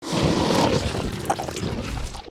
255081e1ee Divergent / mods / Soundscape Overhaul / gamedata / sounds / monsters / bloodsucker / eat_1.ogg 40 KiB (Stored with Git LFS) Raw History
eat_1.ogg